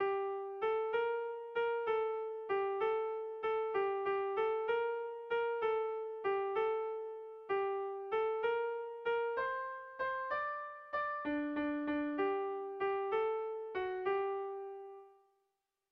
AABD